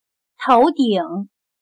头顶/Tóudǐng/la parte superior de la cabeza.